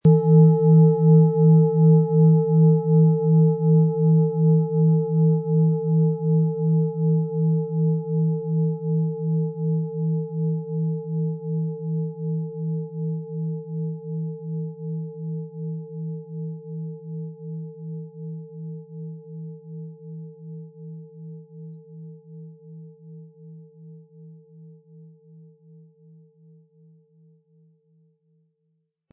• Tiefster Ton: Wasserstoffgamma
Unter dem Artikel-Bild finden Sie den Original-Klang dieser Schale im Audio-Player - Jetzt reinhören.
Der passende Klöppel ist kostenlos dabei, der Schlegel lässt die Klangschale harmonisch und wohltuend anklingen.
PlanetentöneThetawelle & Wasserstoffgamma
MaterialBronze